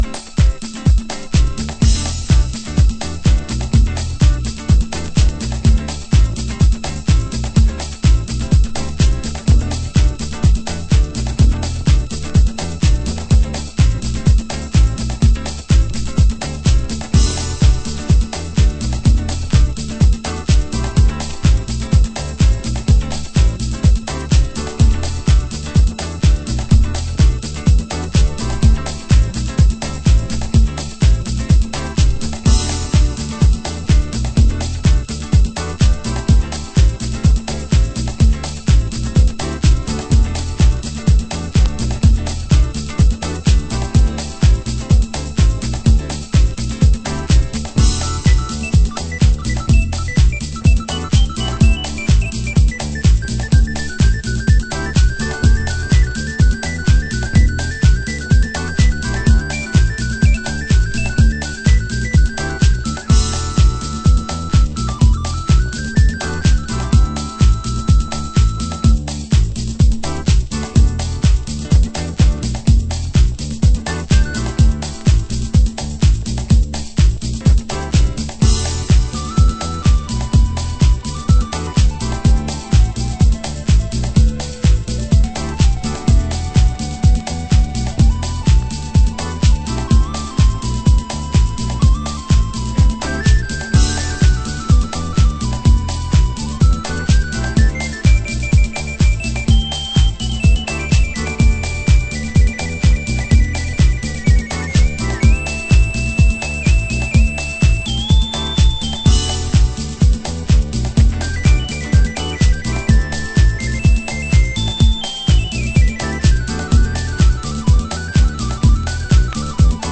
HOUSE MUSIC
盤質：チリパチノイズ有